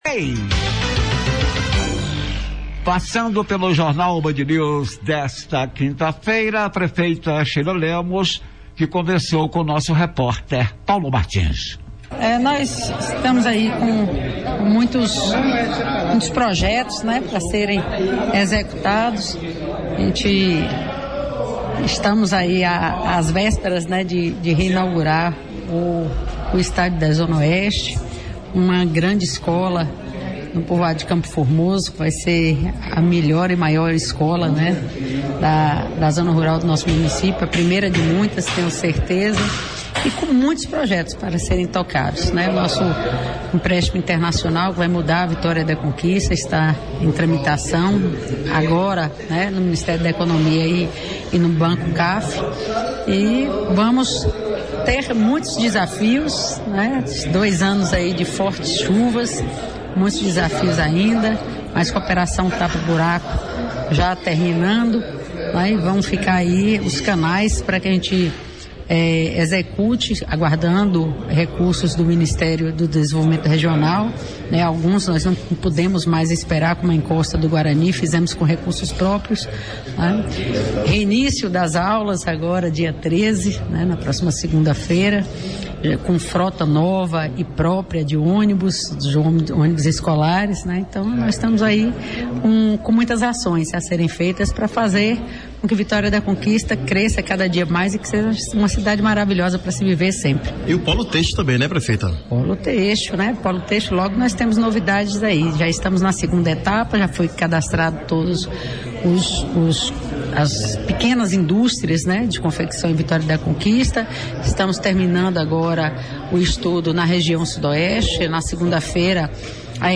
Em entrevista a rádio Bandeirantes, a prefeita fez promessas em cima dos $72 milhões de Dólares.